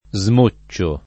vai all'elenco alfabetico delle voci ingrandisci il carattere 100% rimpicciolisci il carattere stampa invia tramite posta elettronica codividi su Facebook smocciare v.; smoccio [ @ m 1©© o ], -ci — fut. smoccerò [ @ mo ©© er 0+ ]